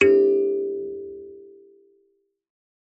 kalimba2_wood
kalimba2_wood-G3-ff.wav